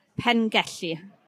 Welsh pronunciation) is a village in the community of Grovesend and Waungron (Welsh: Pengelli a Waungron), City and County of Swansea in Wales.